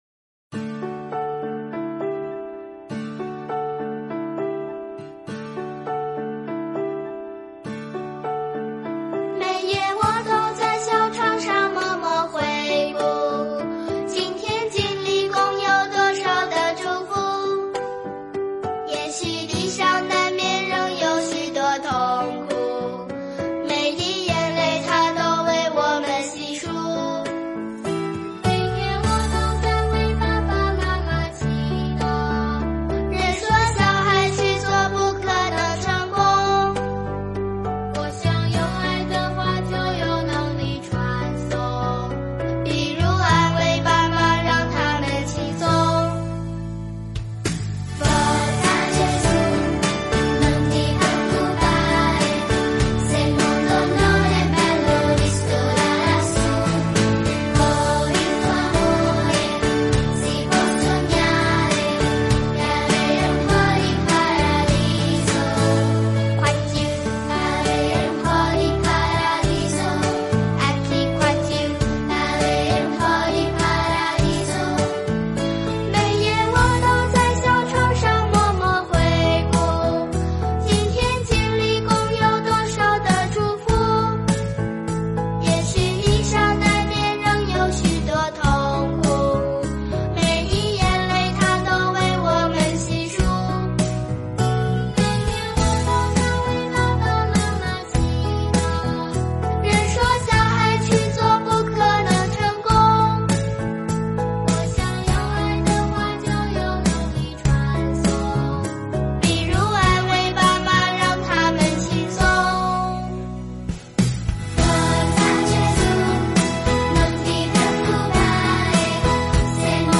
儿童赞美诗｜请不要担心